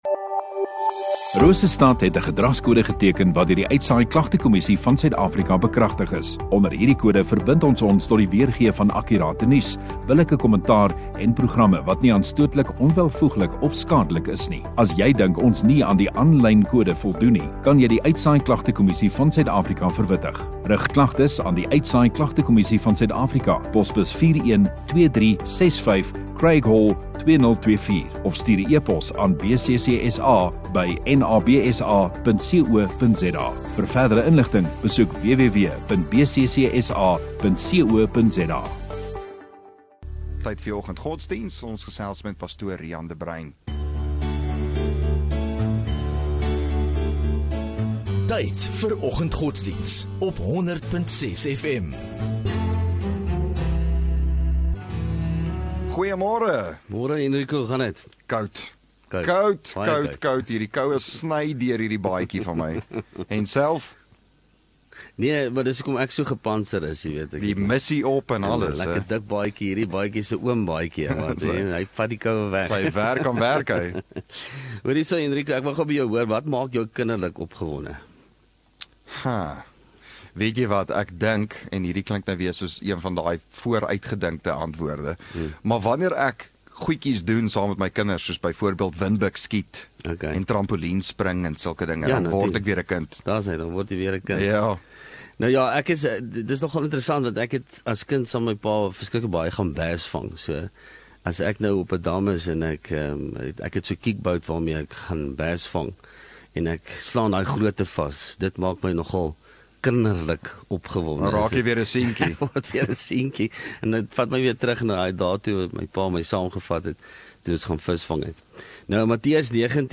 1 Aug Vrydag Oggenddiens